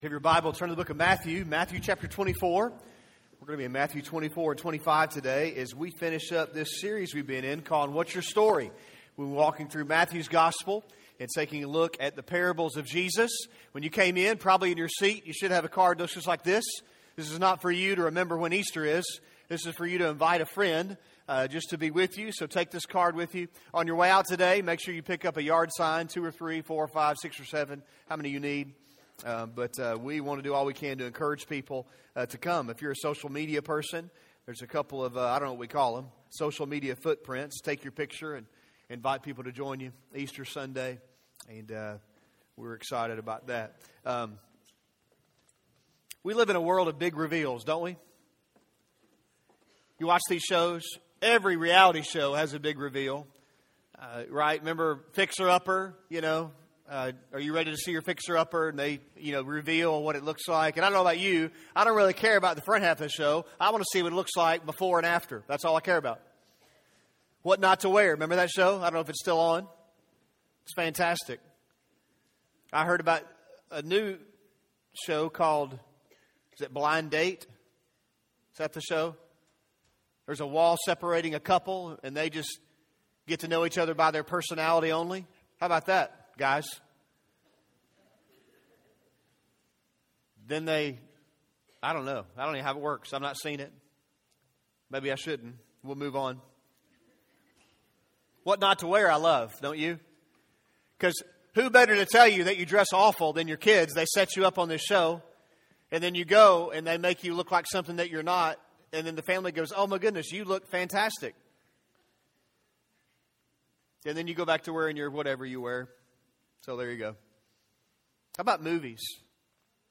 A message from the series "What's Your Story."